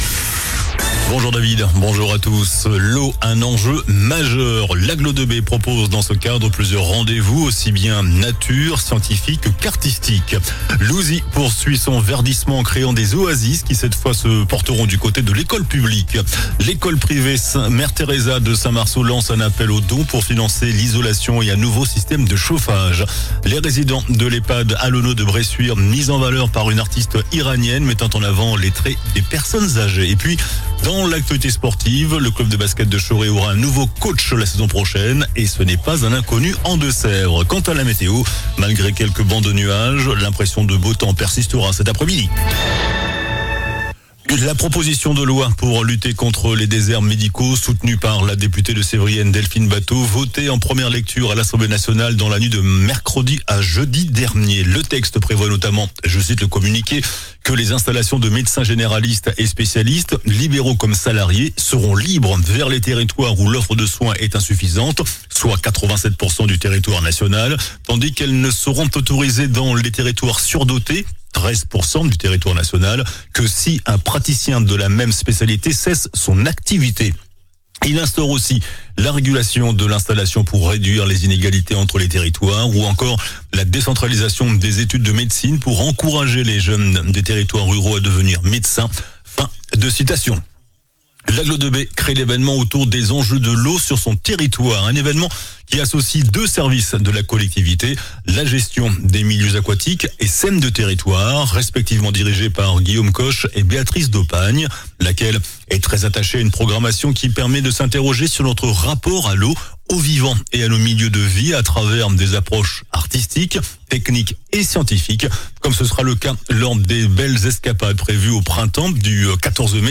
JOURNAL DU VENDREDI 09 MAI ( MIDI )